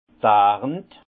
Prononciation 67 Herrlisheim